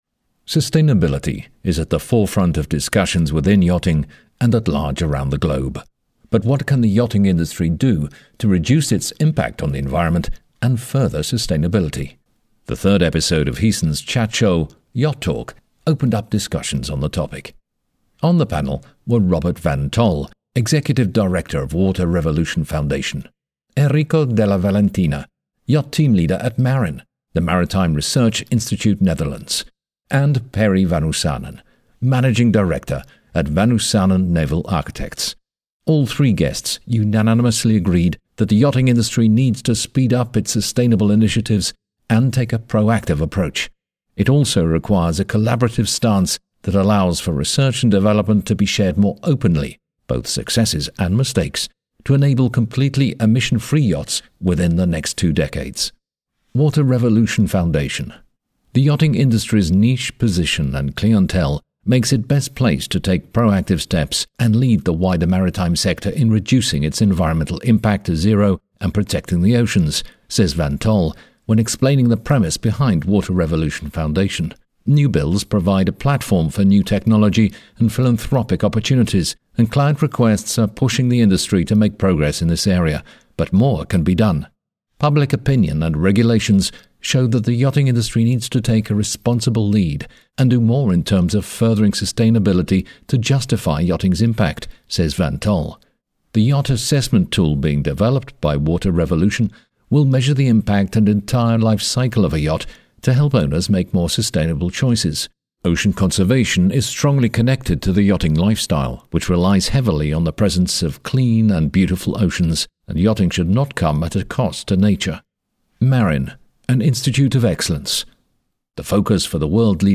But what can the yachting industry do to reduce its impact on the environment and further sustainability? The third episode of Heesen’s chat show YachtTalk opened up discussions on the topic.
When asked the question, ‘What wish would you make for future generations’ the panel had some interesting ideas.